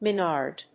/mɪˈnɑrd(米国英語), mɪˈnɑ:rd(英国英語)/